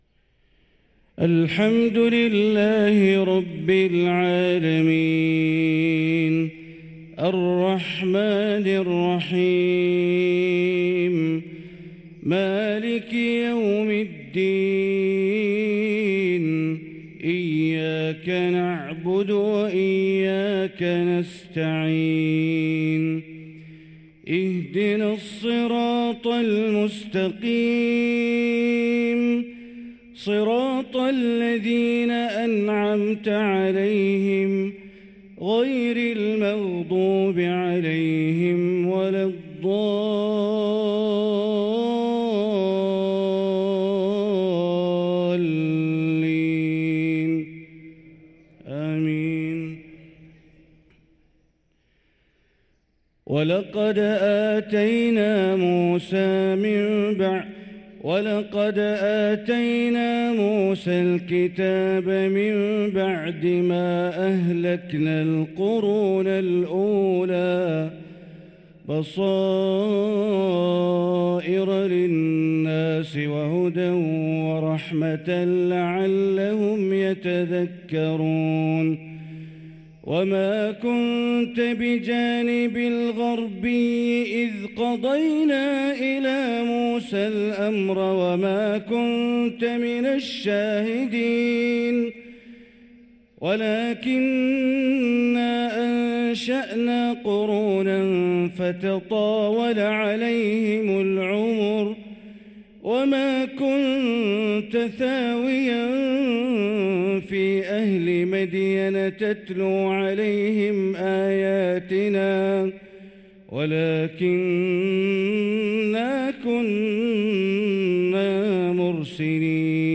صلاة الفجر للقارئ بندر بليلة 14 رجب 1445 هـ